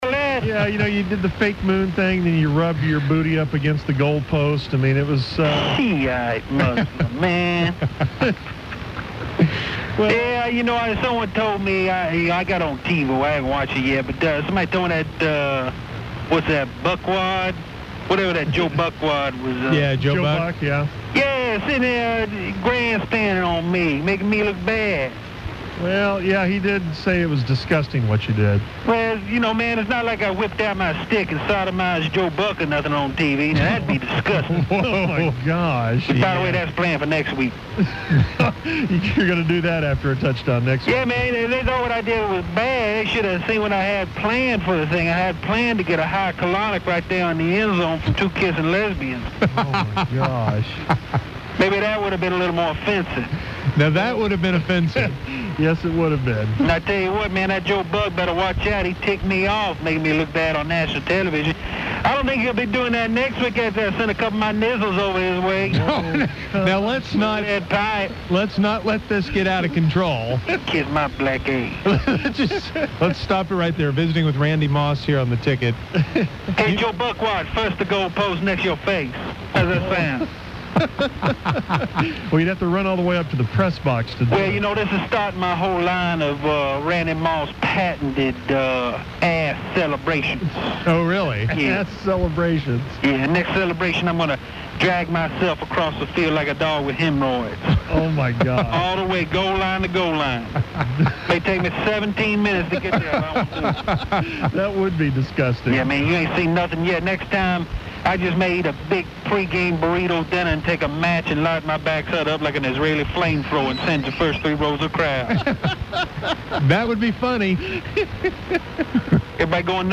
The Musers speak to Randy Moss about his moondance after scoring a touchdown in Green Bay